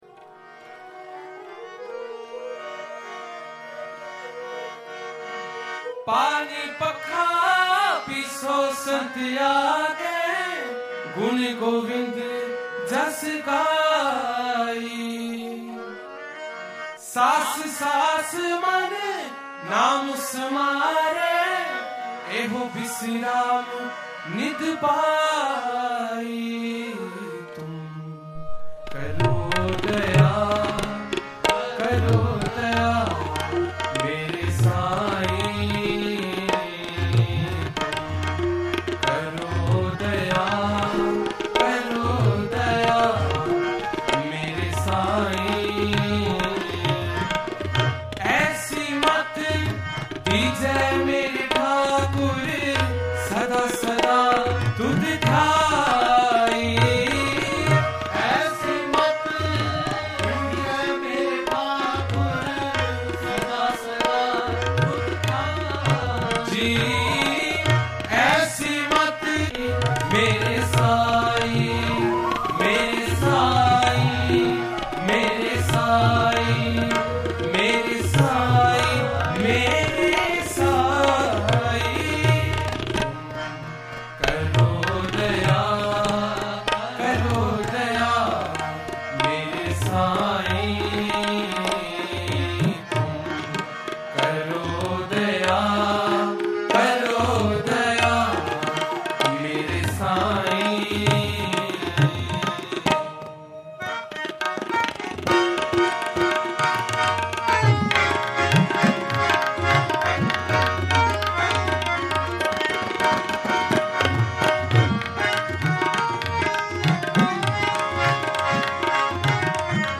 NAAM RAS KIRTAN 2016 (AUDIO) – DAY 1 PART 2